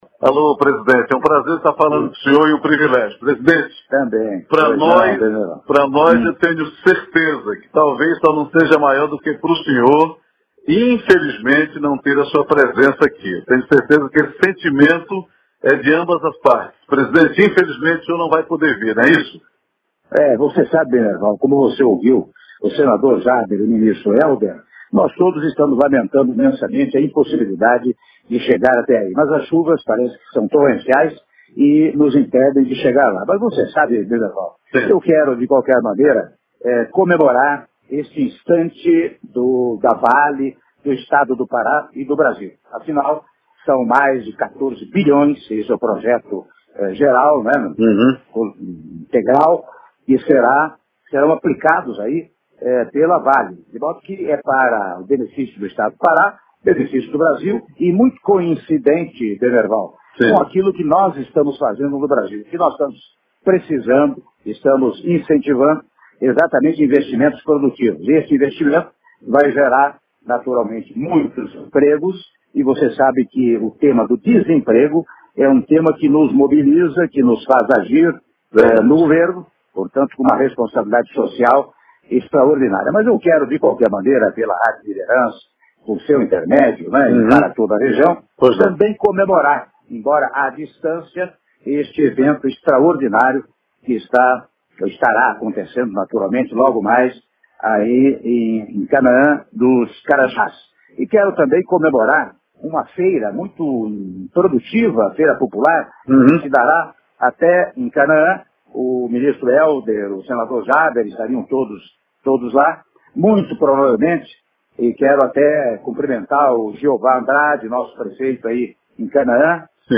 Áudio da entrevista concedida pelo Presidente da República, Michel Temer, à Rádio Liderança Pará (04min37s)